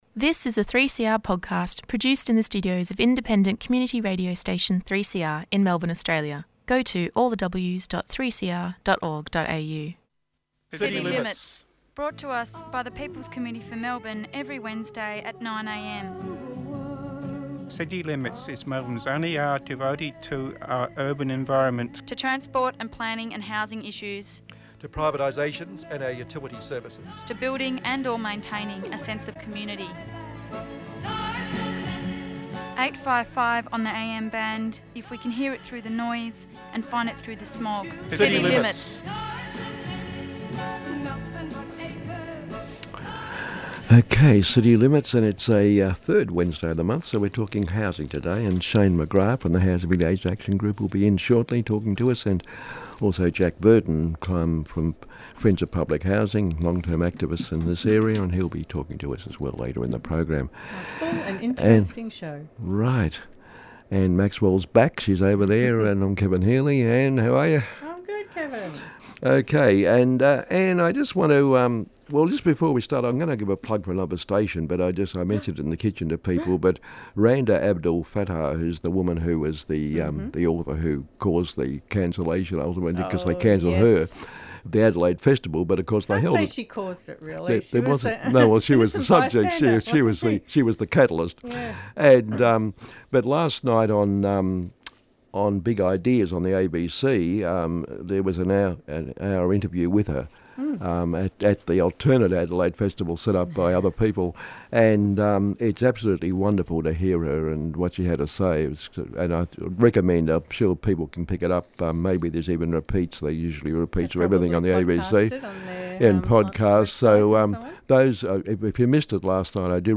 This week we interview